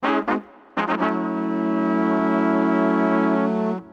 FsharpHornStack.wav